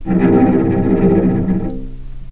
healing-soundscapes/Sound Banks/HSS_OP_Pack/Strings/cello/tremolo/vc_trm-C2-pp.aif at 01ef1558cb71fd5ac0c09b723e26d76a8e1b755c
vc_trm-C2-pp.aif